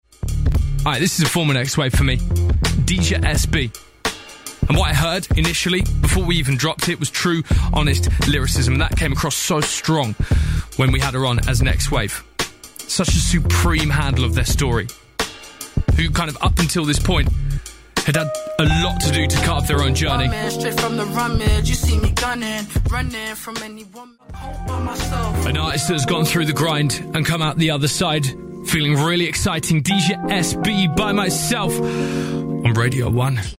ハードコアヒップホップの魂は死なず！